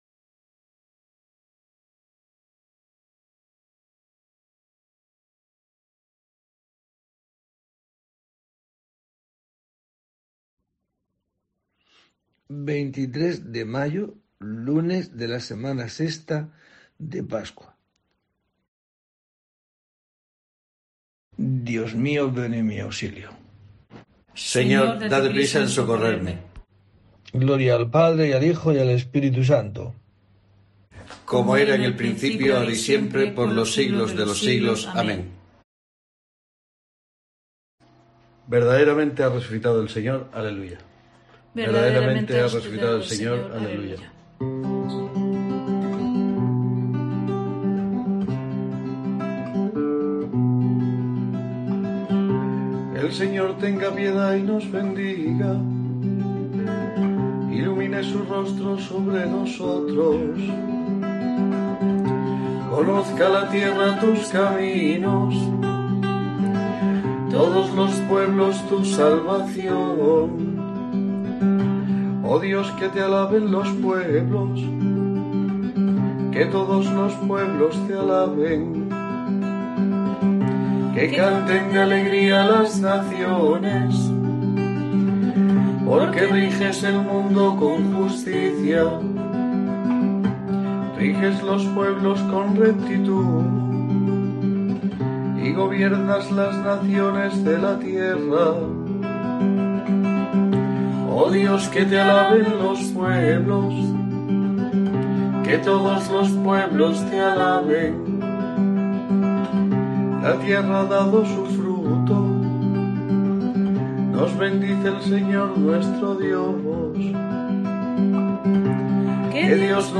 23 de mayo: COPE te trae el rezo diario de los Laudes para acompañarte